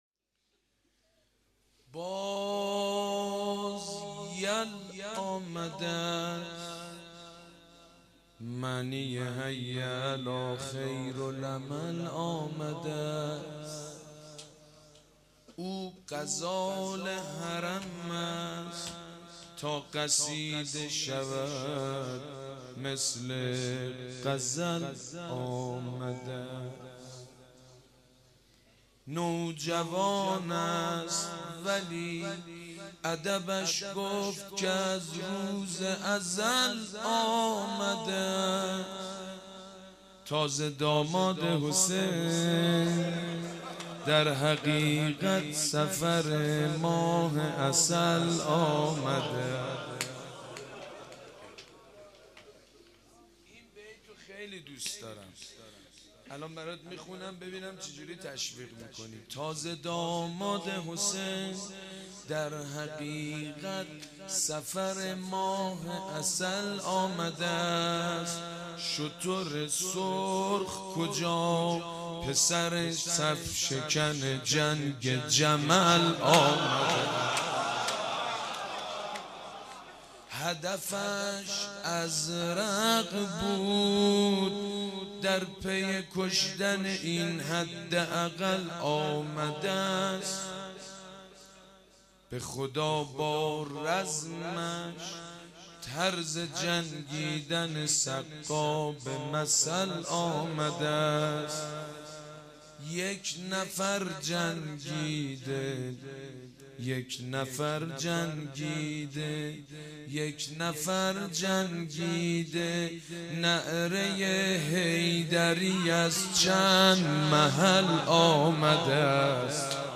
باز یل آمده است معنیِ...(روضه)